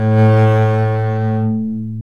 Index of /90_sSampleCDs/Roland L-CD702/VOL-1/STR_Cb Bowed/STR_Cb1 mf vb